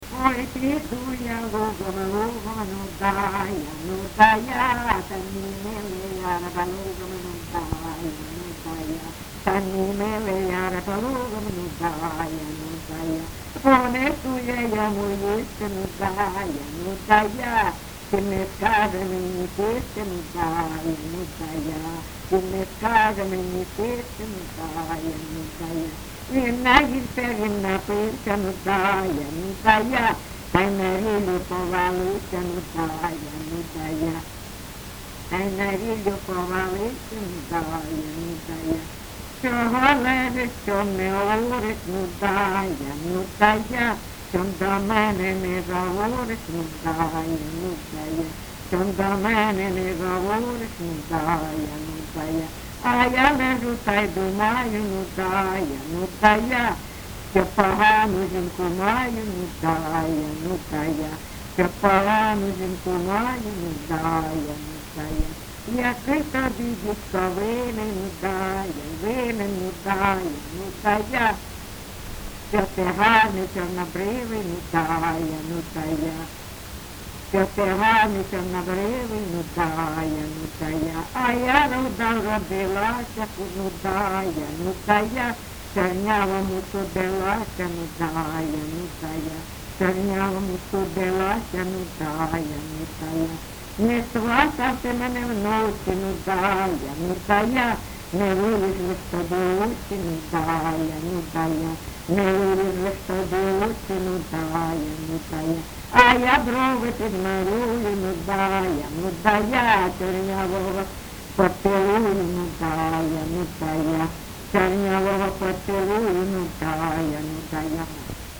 ЖанрПісні з особистого та родинного життя, Жартівливі
Місце записус. Привілля, Словʼянський (Краматорський) район, Донецька обл., Україна, Слобожанщина